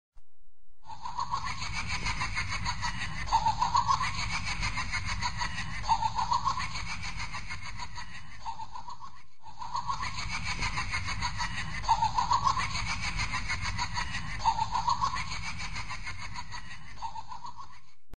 Play Jigsaw Laugh - SoundBoardGuy
Play, download and share Jigsaw Laugh original sound button!!!!
jigsaw-laugh.mp3